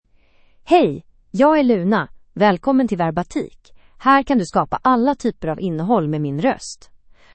LunaFemale Swedish AI voice
Luna is a female AI voice for Swedish (Sweden).
Voice sample
Listen to Luna's female Swedish voice.
Female
Luna delivers clear pronunciation with authentic Sweden Swedish intonation, making your content sound professionally produced.